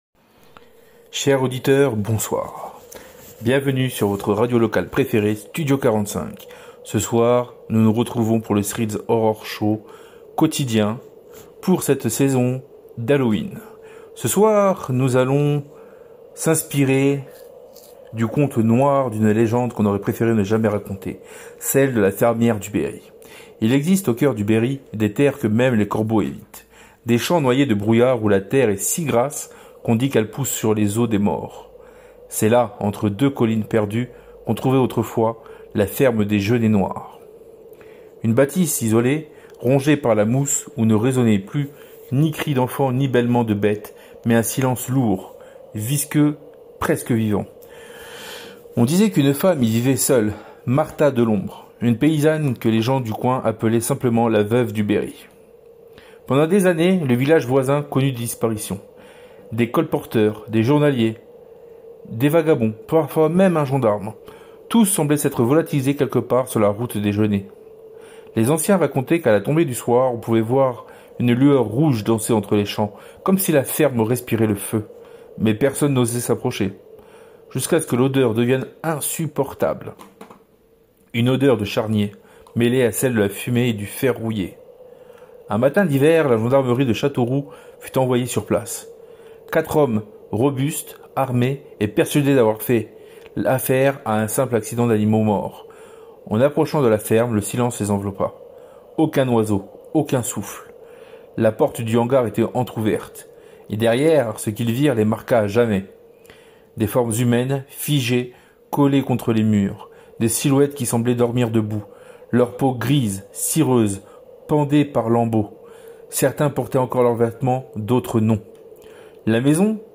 Dans cet épisode de la série Threads of Horror, Studio 45 nous plonge dans une fiction sonore terrifiante, inspirée des légendes rurales et des croyances oubliées.Entre rumeurs, superstitions et drames enfouis, l’histoire dévoile lentement la vérité — ou ce qu’il en reste — derrière les murs d’une ferme où le temps semble s’être arrêté.
Une expérience d’écoute sombre, immersive et saisissante, où les bruits de la campagne deviennent les échos d’une présence qu’on ne peut ni voir… ni fuir.